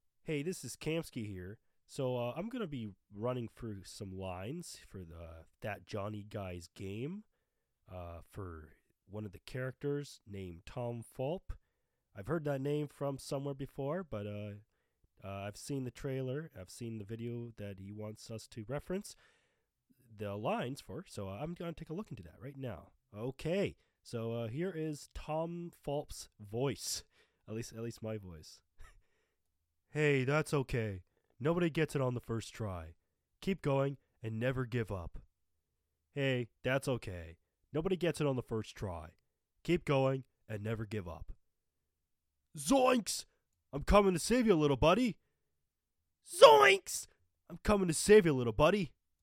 Comedy
Voice